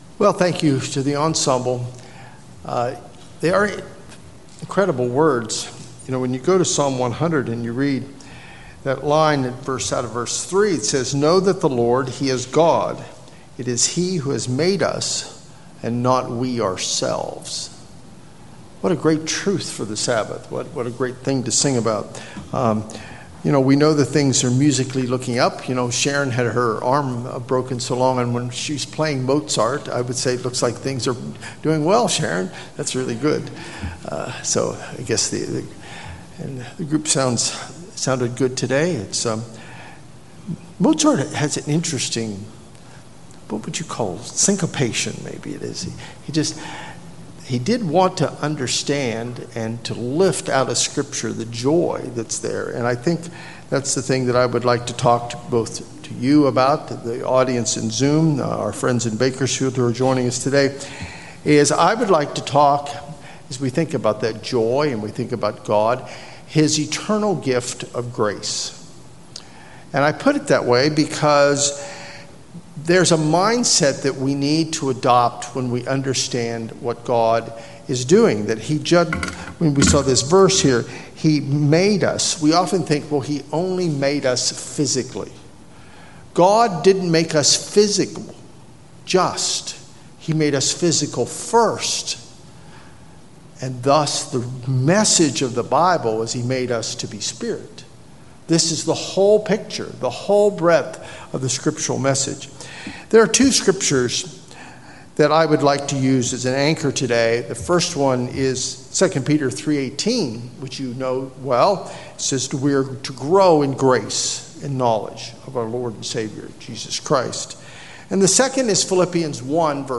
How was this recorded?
Given in Los Angeles, CA Bakersfield, CA